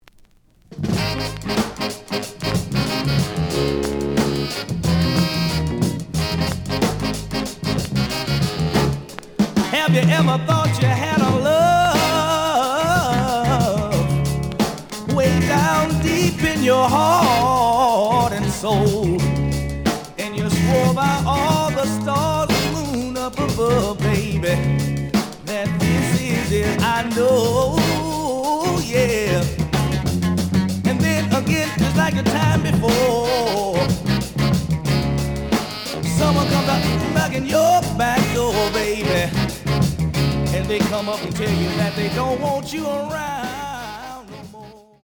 The audio sample is recorded from the actual item.
●Genre: Funk, 60's Funk
Some click noise on B side due to scratches.